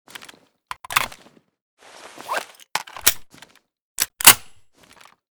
svd_reload_empty.ogg